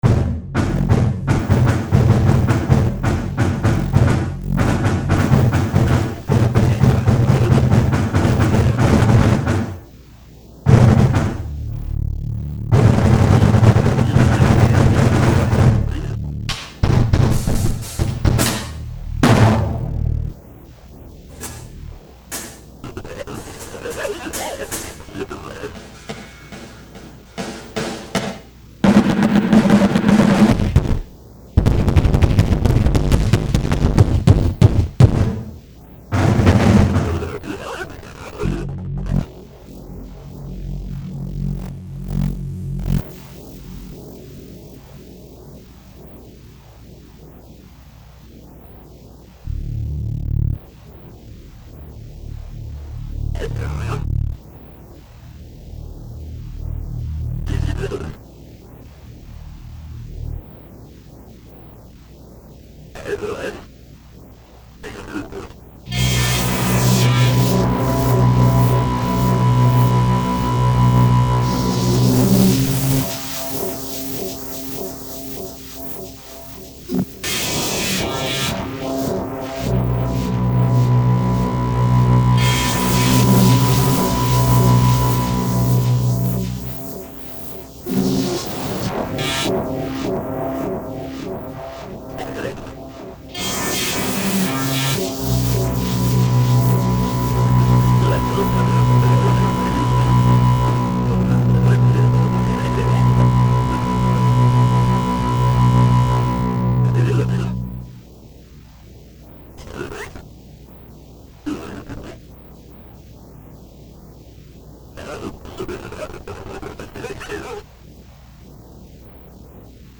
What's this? grindcore, noisecore,